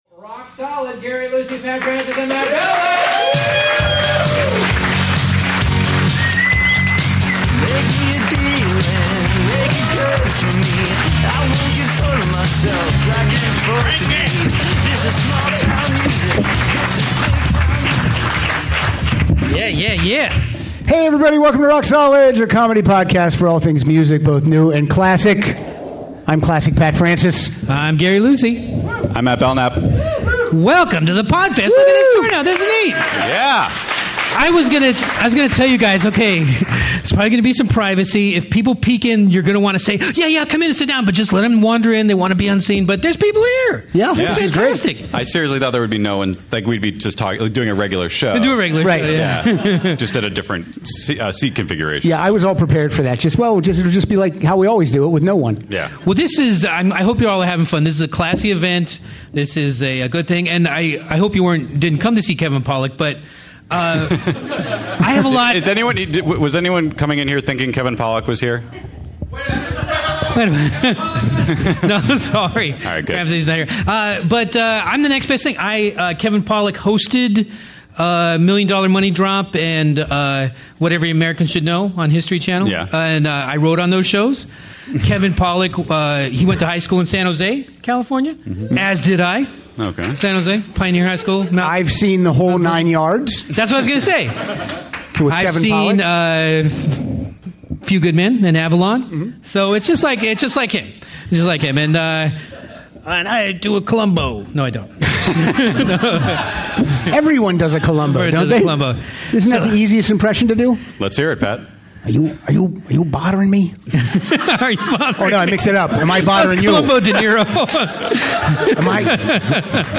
Performing live at the Los Angeles Podcast Festival, the guys play their favorite "goodbye" songs. (Recorded on October 4, 2013 at the Le Meridien Delfina in Santa Monica, California.)